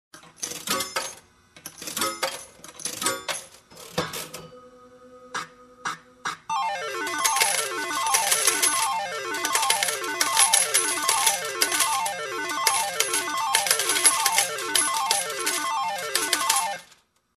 Звуки игрового автомата
Забросили монетку в автомат, а он как даст. Звук джекпота и выпадение копеек